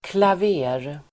Ladda ner uttalet
Uttal: [klav'e:r]